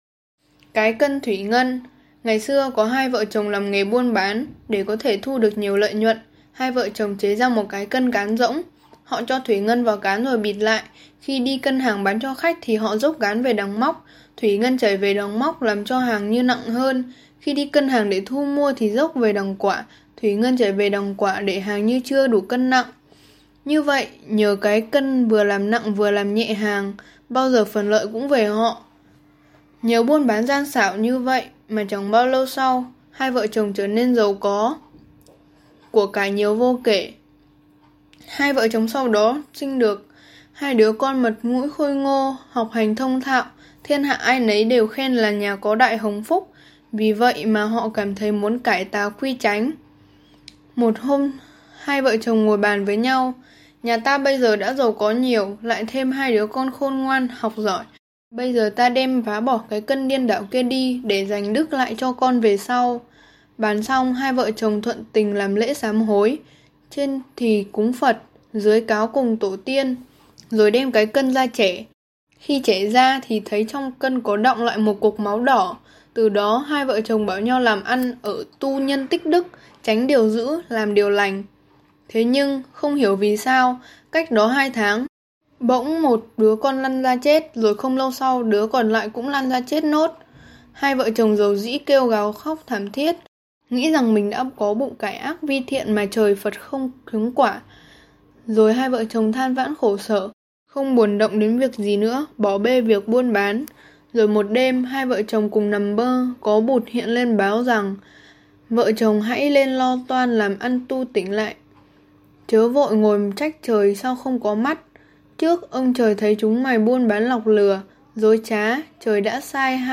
Sách nói | Truyện: Cái cân thủy ngân